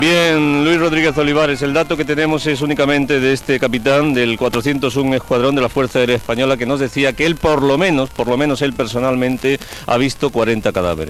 Informació des de les proximitats de les restes d'un dels avions.
Informatiu